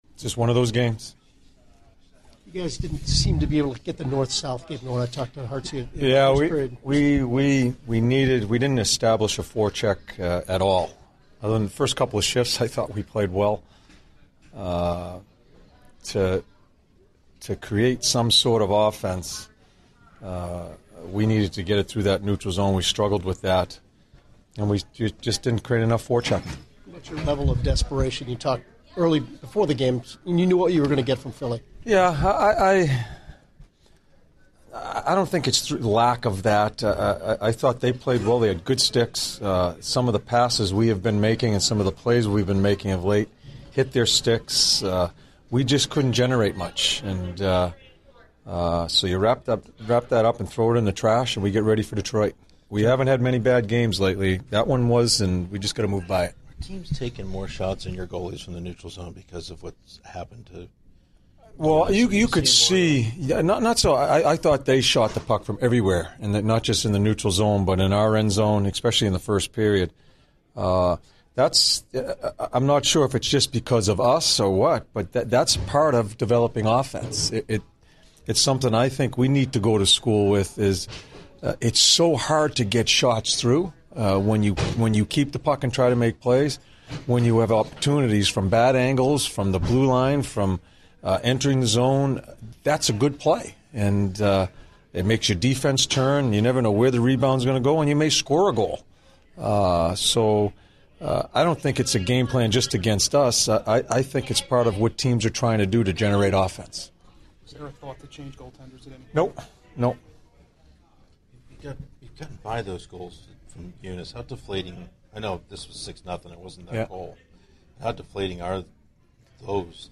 John Tortorella Post-Game 03/05/16